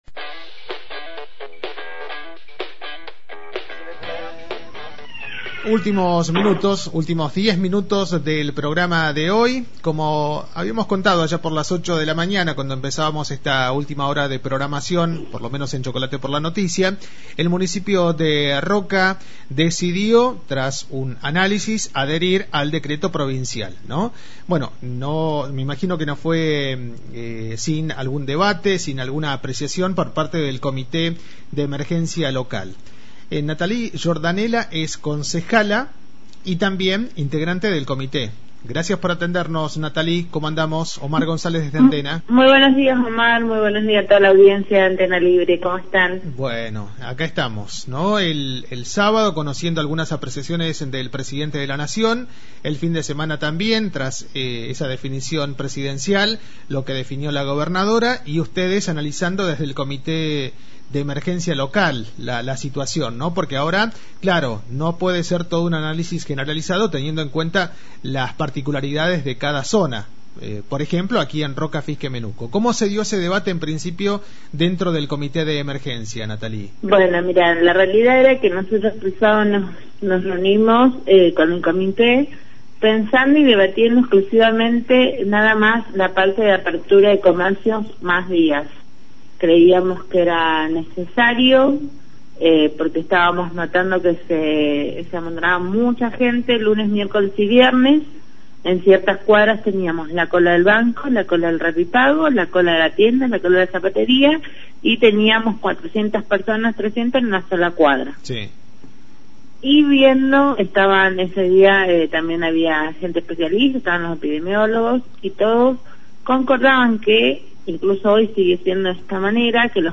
Natali Giordanella, Concejala e integrante del Comité de Emergencia de la ciudad habló para los micrófonos de Antena Libre sobre la última reunión que mantuvieron y la flexibilización de algunas actividades, como la apertura de comercios por más días y los servicios profesionales.